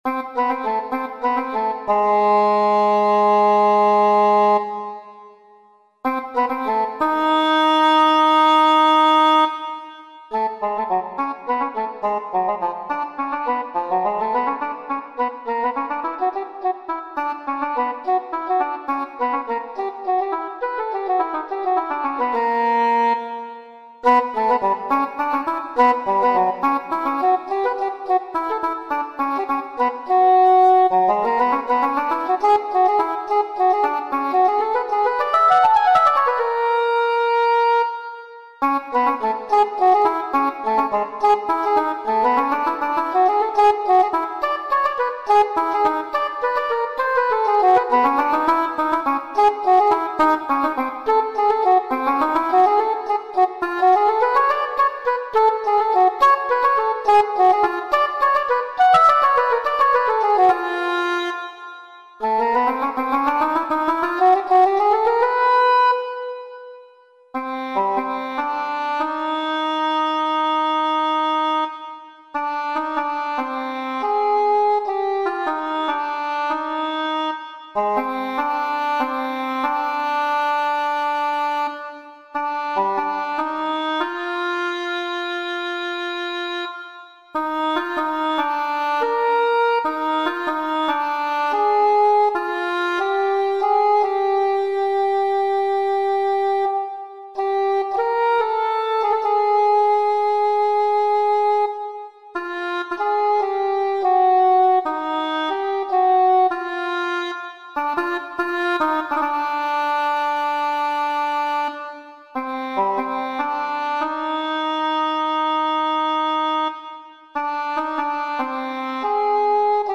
Voicing: English Hn